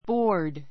bɔ́ː r d